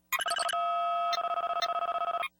repairstart.ogg